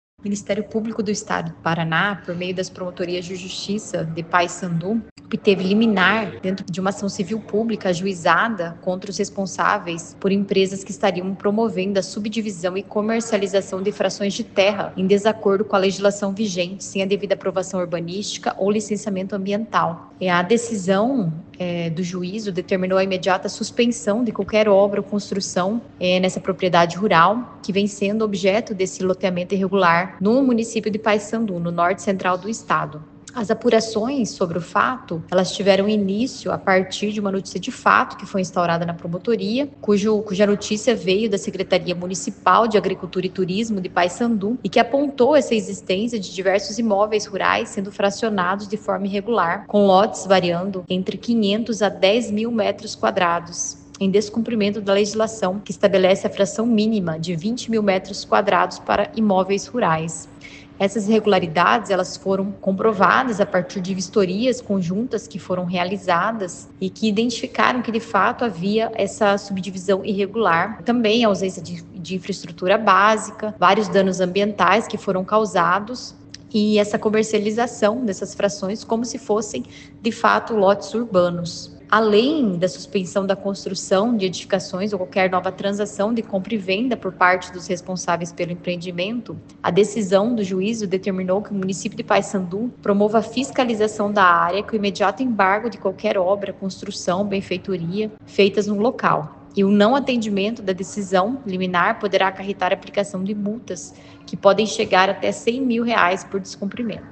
Ouça o que diz a promotora de Justiça, Vivian Christiane Santos Klock.